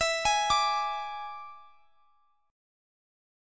E7 Chord
Listen to E7 strummed